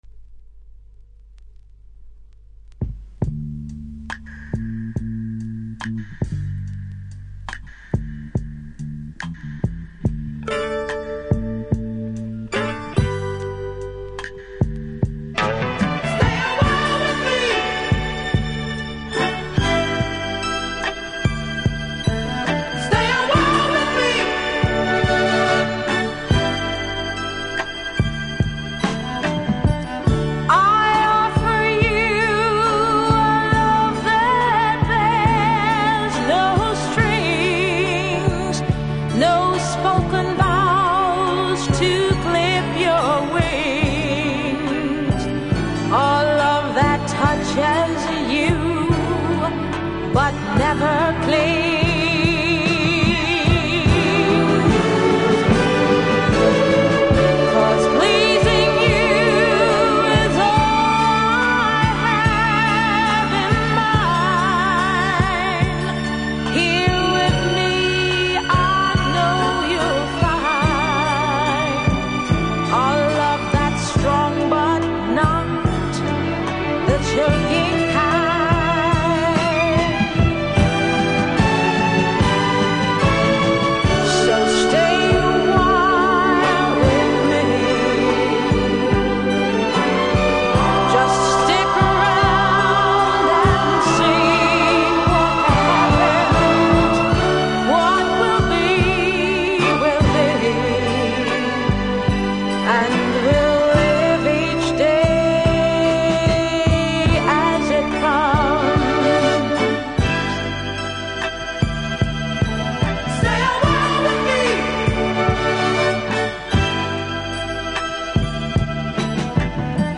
70'S FEMALE
「ずーっと一緒にいてね」とのラブソング♪